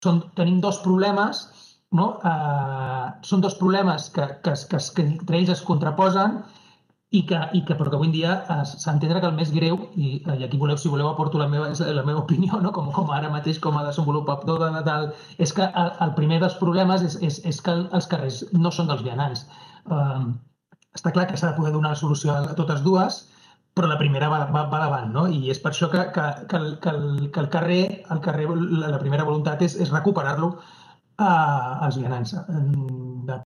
Declaracions